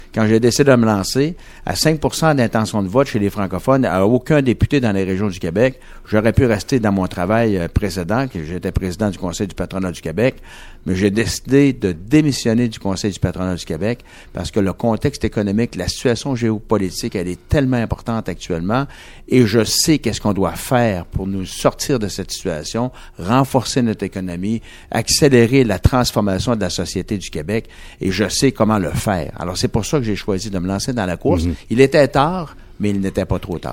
En entrevue à Radio Beauce, il estime être le mieux placé pour redonner l’étiquette économique au parti.